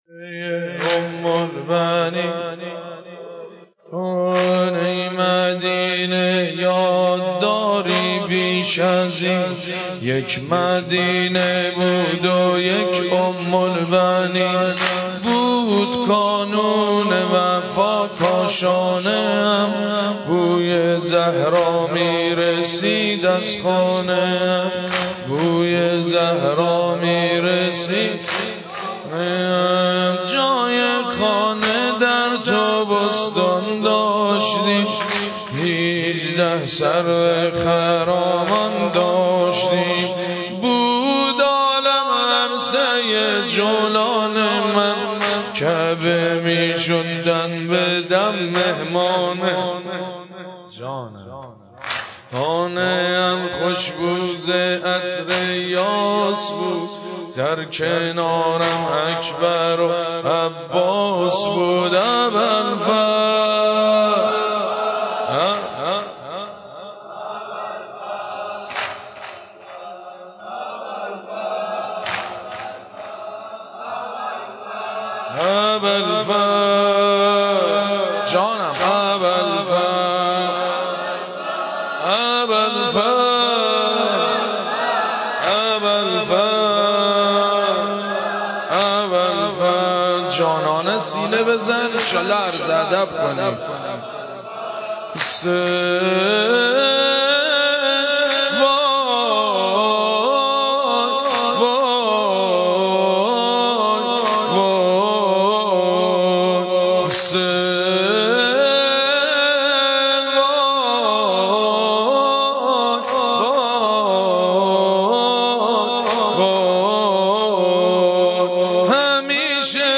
مداحی/ ذکر مصیبت حضرت ام البنین (س)